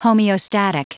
Prononciation du mot homeostatic en anglais (fichier audio)
Prononciation du mot : homeostatic